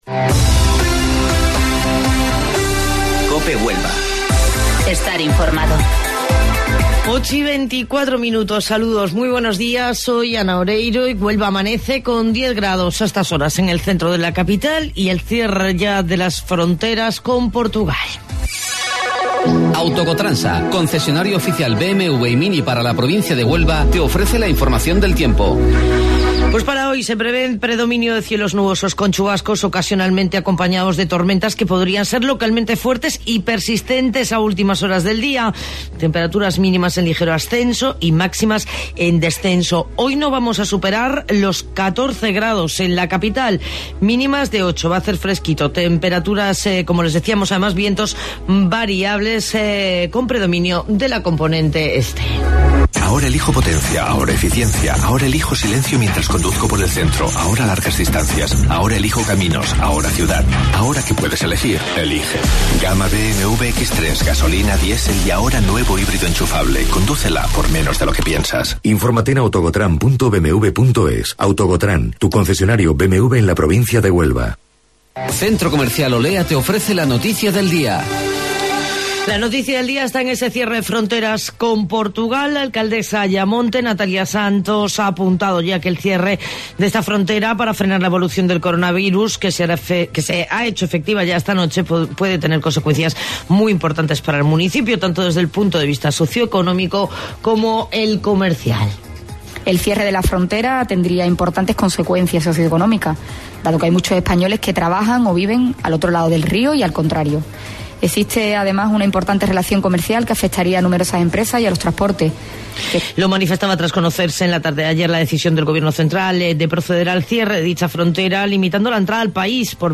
AUDIO: Informativo Local 08:25 del 17 Marzo